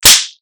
spank.ogg